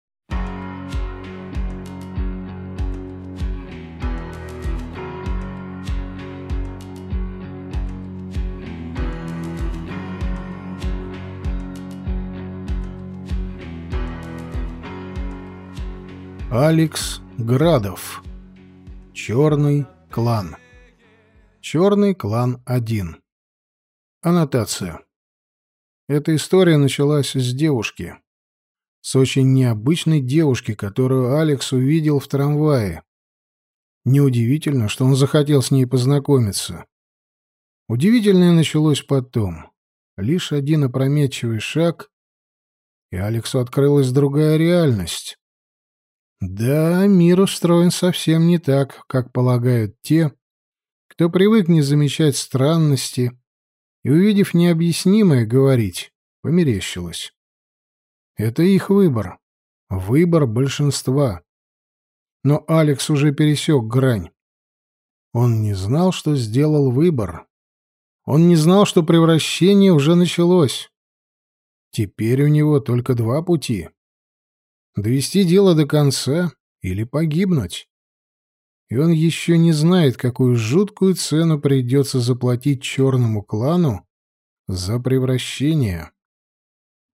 Аудиокнига Черный клан | Библиотека аудиокниг